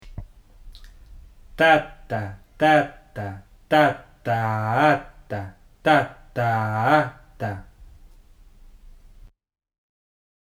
Syncopation
The following audio will help you to practise the syncopation,
ejerciciosincopa.mp3